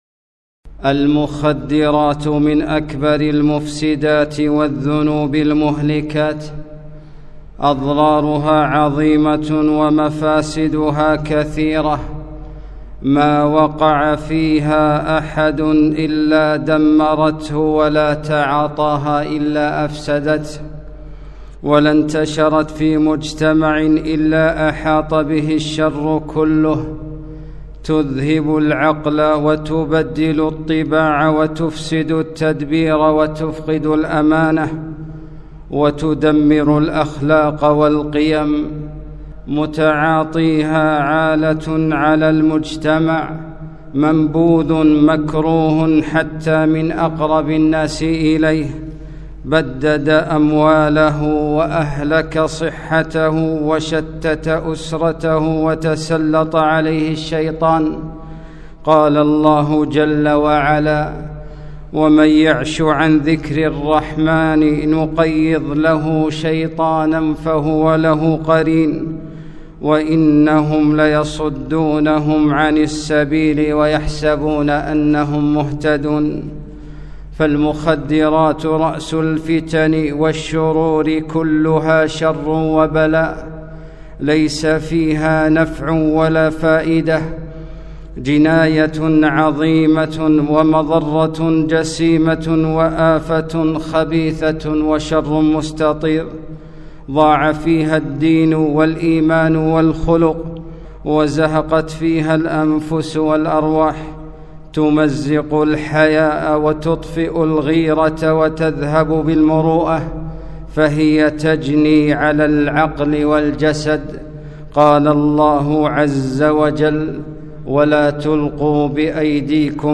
خطبة - ضرر المخدرات